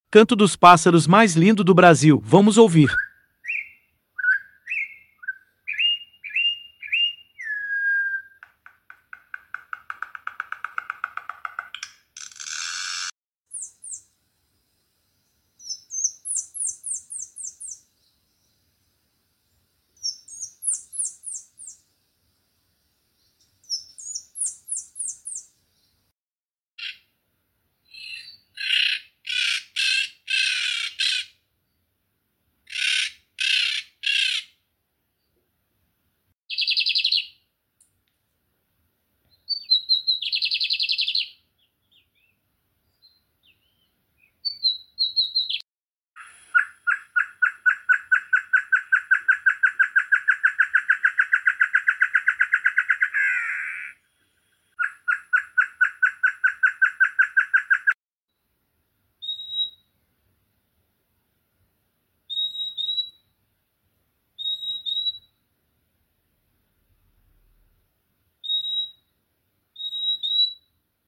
canto dos pássaros mais lindo sound effects free download
canto dos pássaros mais lindo do Brasil